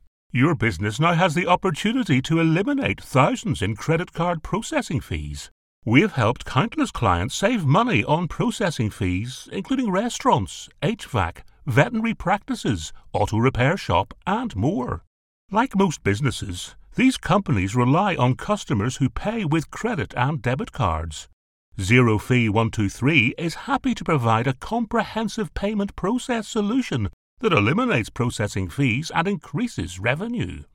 Western Union Voice Over Commercial Actor + Voice Over Jobs
I have a friendly, kind, warm and approachable conversational voice.
My voice has the just-right combination of calming authority and genuine warmth.